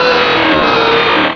Cri de Rayquaza dans Pokémon Rubis et Saphir.